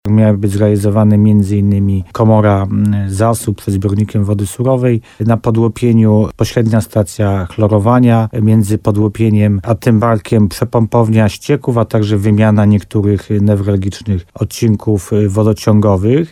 – W ramach prac ma być wykonanych kilka różnych zadań, które mogą zminimalizować dotychczasowe problemy – mówił w programie Słowo za Słowo w RDN Nowy Sącz wójt Tymbarku, Paweł Ptaszek.